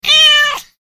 Cat Meow 2